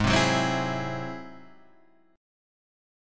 G# Augmented 7th